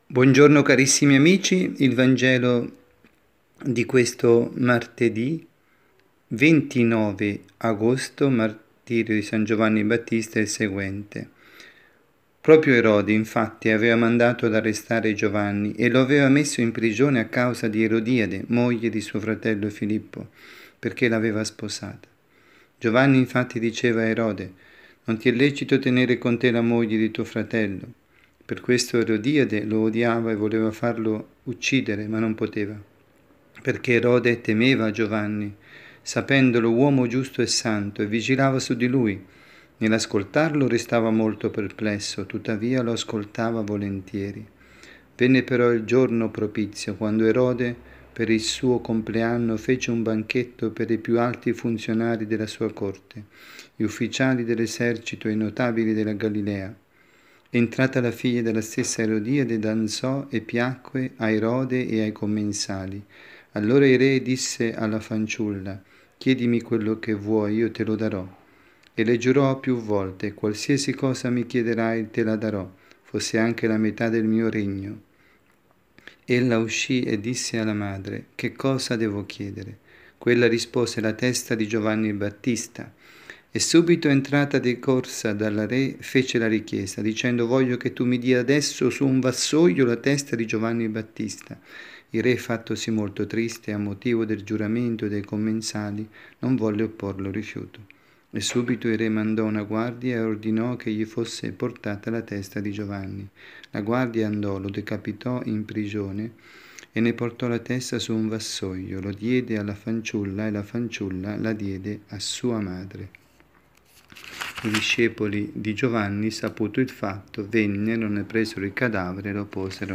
Catechesi
dalla Parrocchia S. Rita, Milano